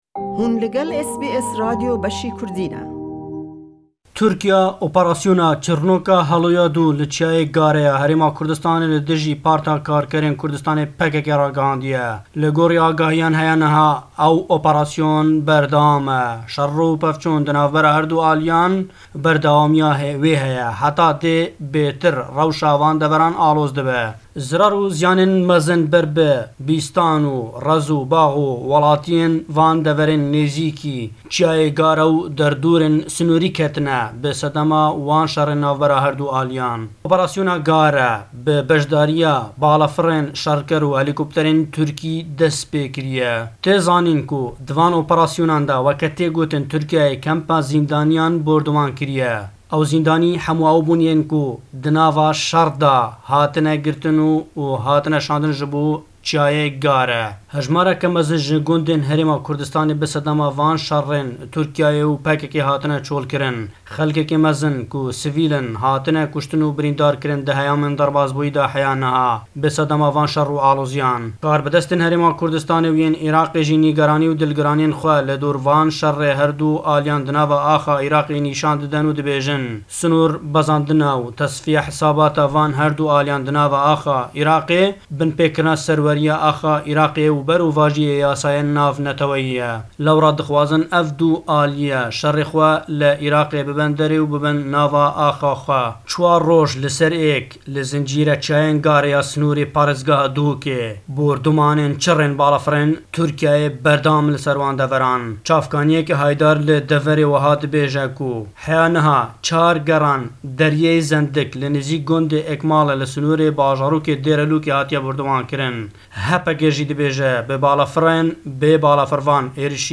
Le em raporte da le Hewlêre we,